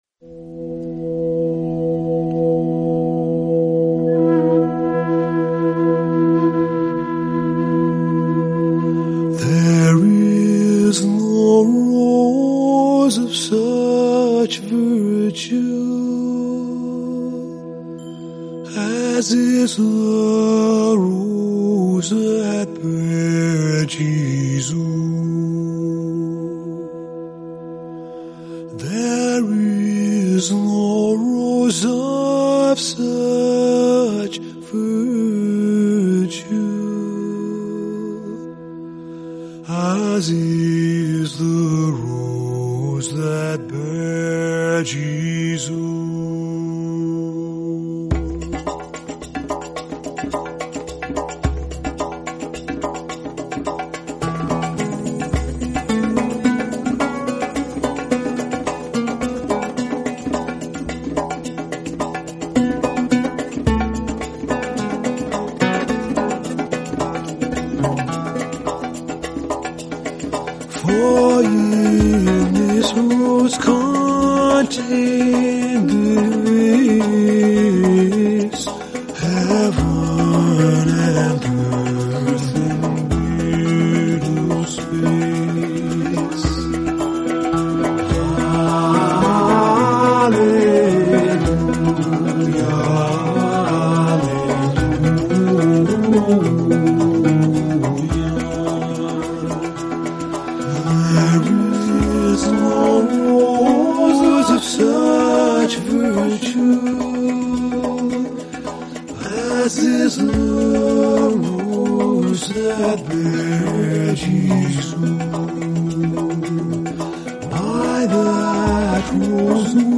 Anonymous Fifteenth-Century English Carol